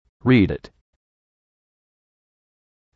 Généréralement, quand un mot se termine par un son consonne, et qu'il est suivi par un son voyelle, on fait la liaison:
read_it > rea - dit
Quand on progresse, il faut faire en sorte que le "d" à la fin de "read_it" appartienne à la fois à "rea" et à "it".